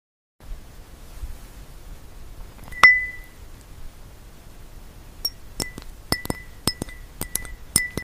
🥒✨ Satisfying ASMR meets AI magic — you won’t believe your eyes!